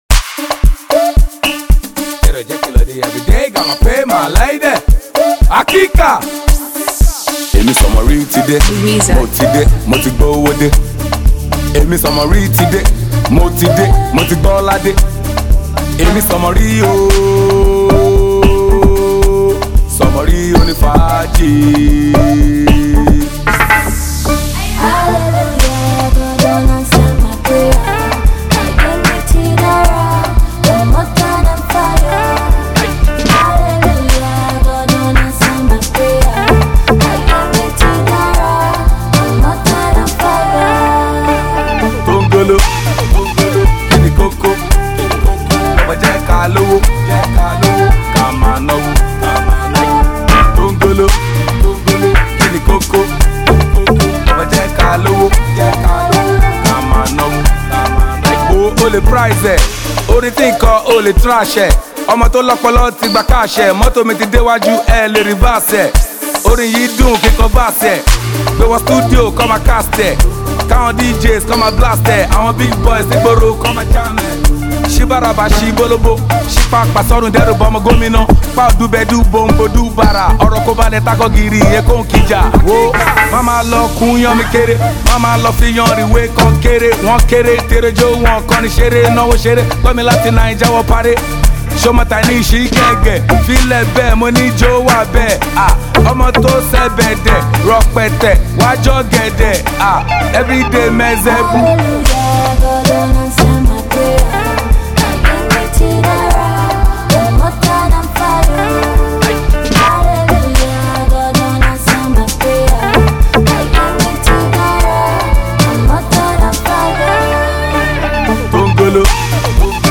soulful track
smooth blend of Afropop and rap
Afropop